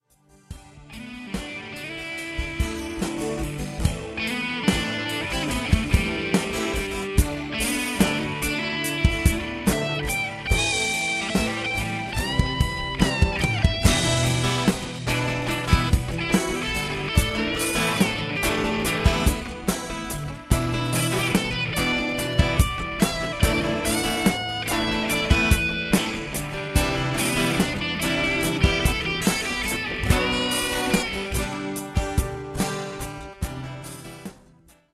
Solo theme